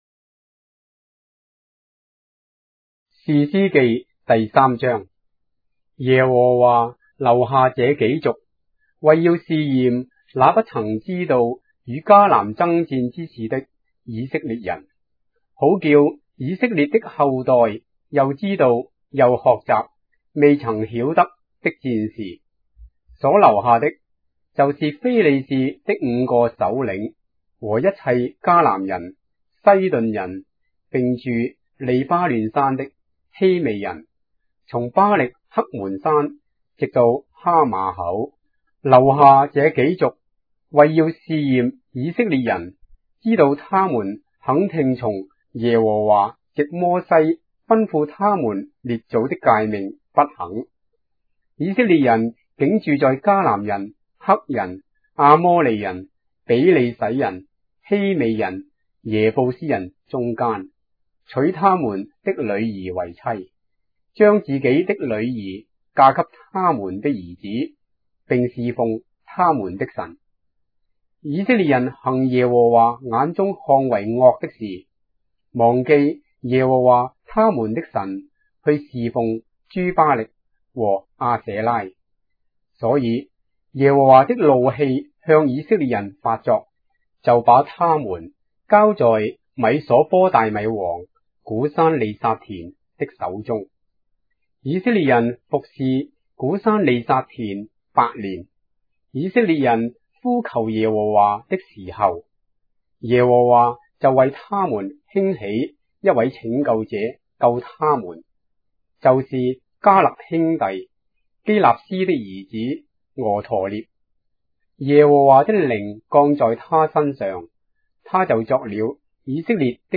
章的聖經在中國的語言，音頻旁白- Judges, chapter 3 of the Holy Bible in Traditional Chinese